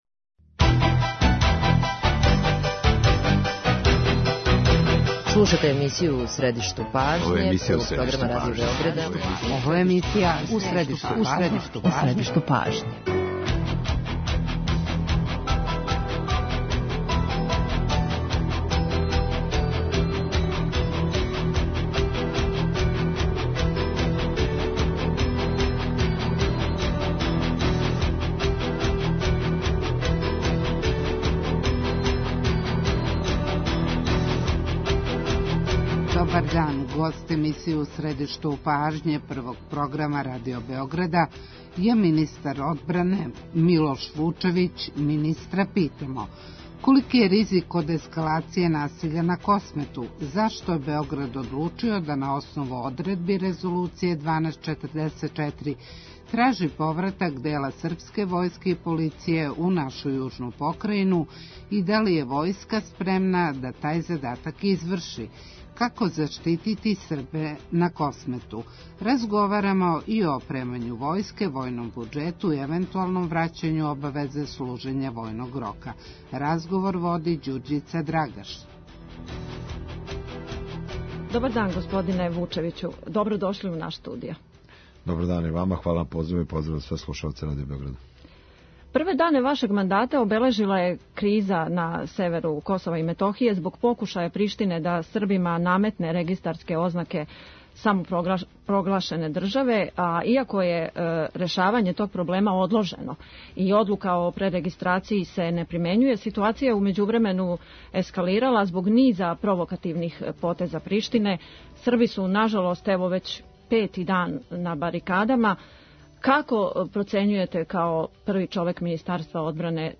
Министар одбране Милош Вучевић, интервју